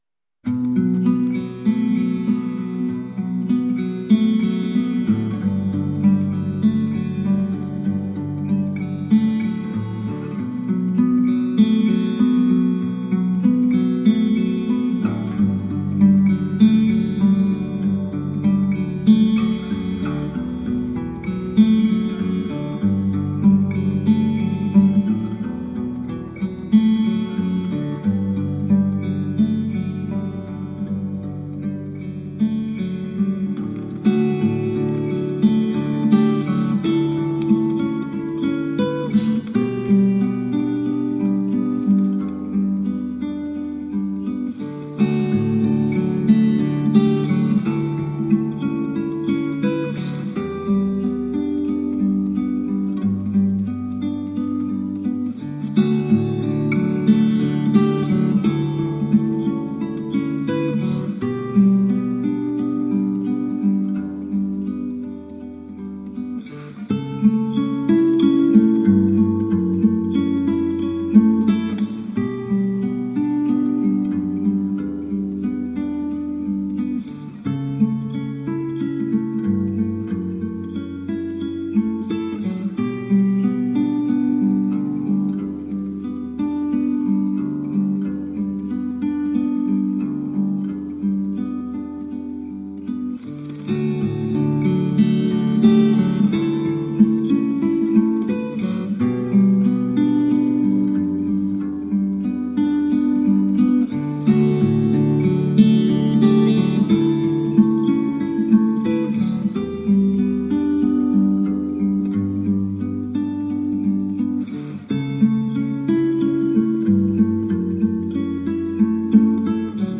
Acoustic new age and jazz guitar..
solo acoustic guitar pieces